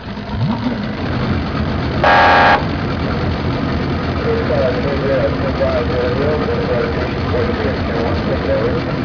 descargar sonido mp3 guardia costera 2
nautical041.mp3